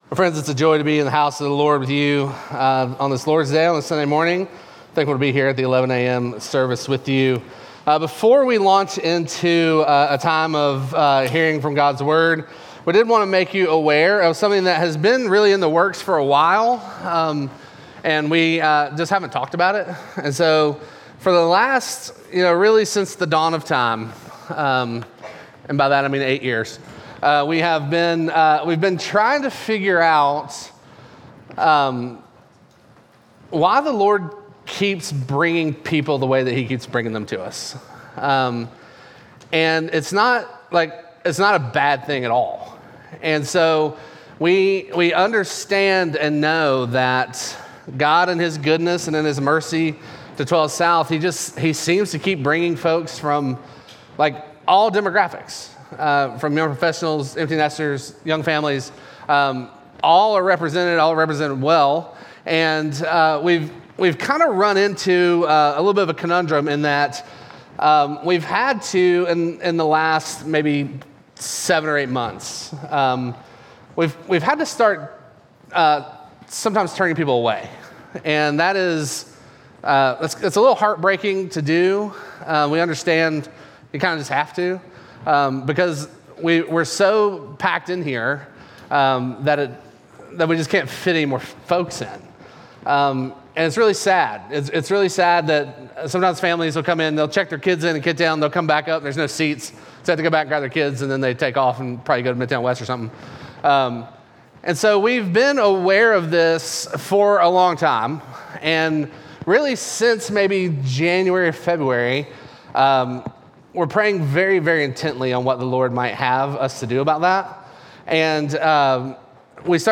Midtown Fellowship 12 South Sermons Hot Pursuit Aug 04 2024 | 00:35:07 1x 00:00 / 00:35:07 Subscribe Share Apple Podcasts Spotify Overcast RSS Feed Share Link Embed